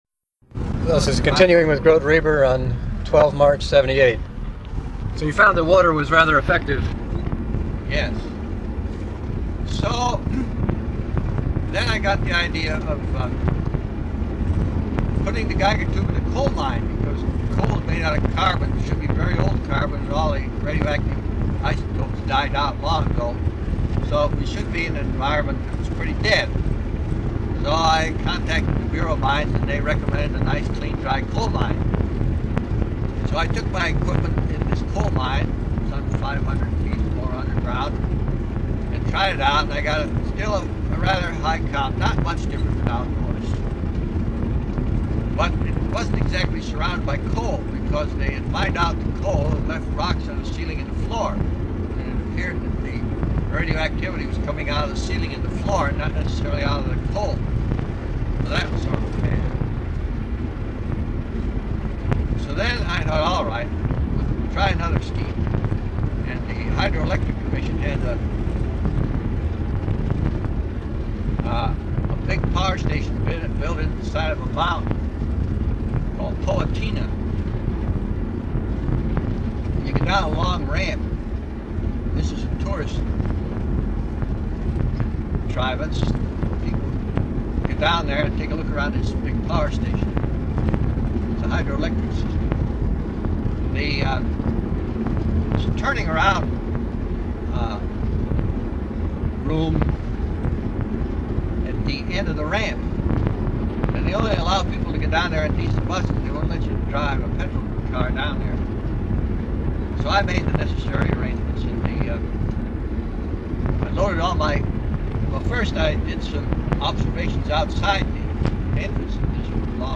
Interviewed 12 March 1978 in Tasmania, length of interview: 90 minutes.
Oral History
Audio cassette tape